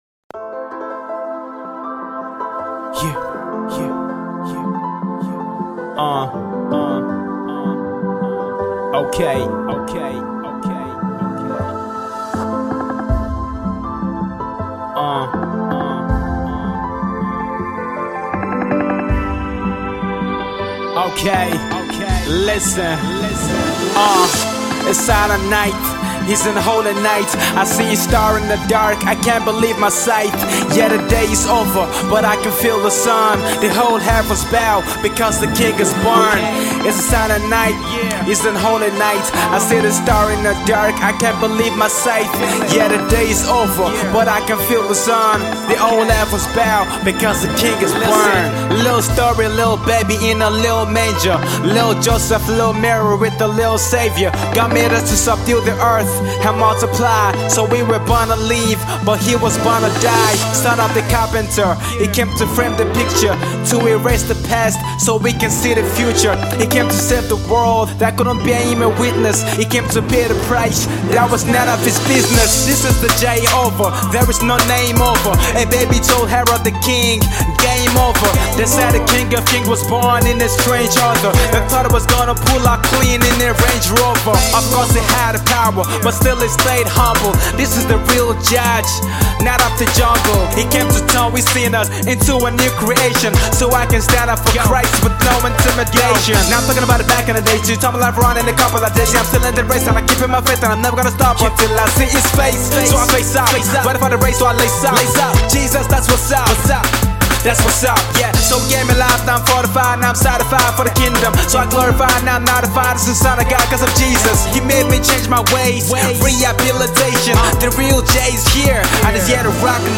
Christian rapper